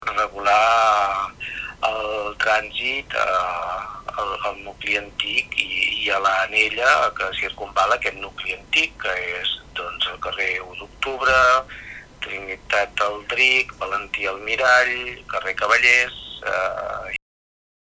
L’alcalde Enric Marquès assegura que l’objectiu el projecte de pacificació de l’entorn del Carrer Ample és regular el trànsit a la zona que envolta del nucli antic de la Bisbal.